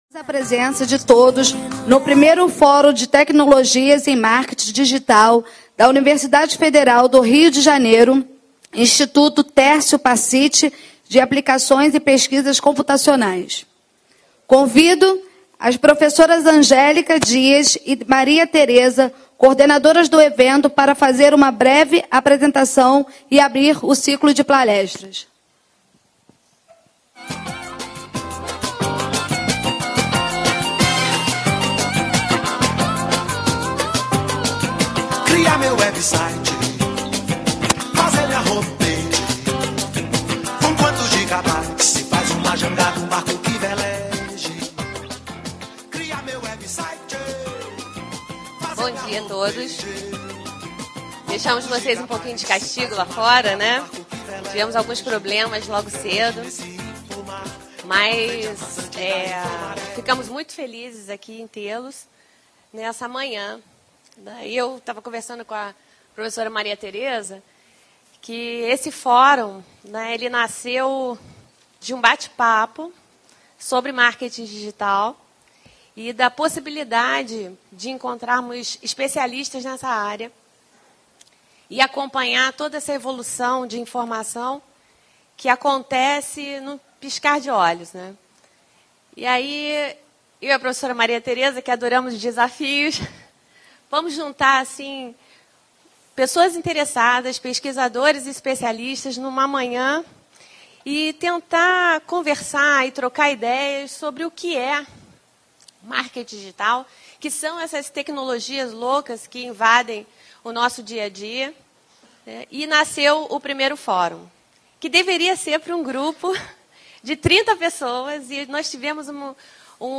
Abertura